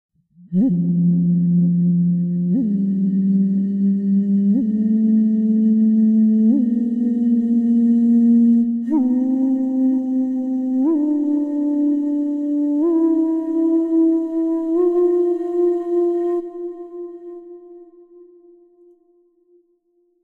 音のなる壺（陶器）、森のほこらの響き
七沢笛
吹口：リコーダー形式 キー：Ｆ